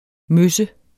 Udtale [ ˈmøsə ]